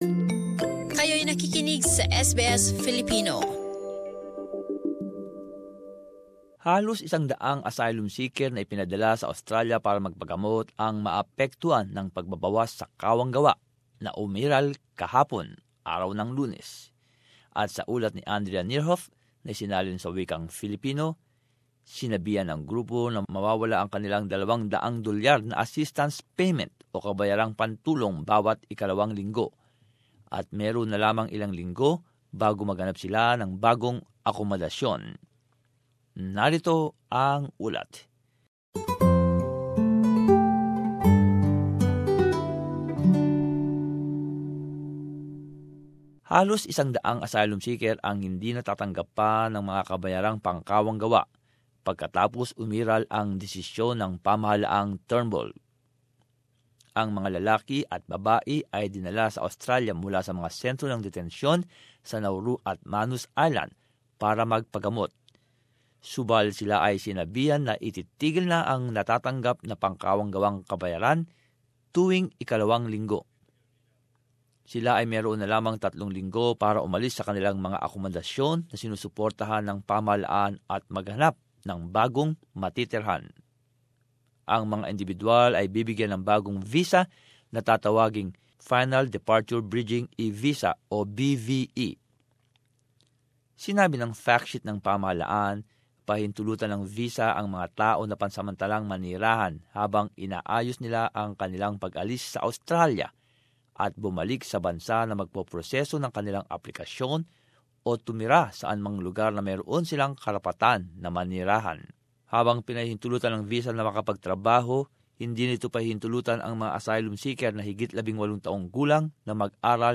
As this report shows, the group has been told they are losing their $200-a-fortnight assistance payment, and have just weeks to find new accommodation.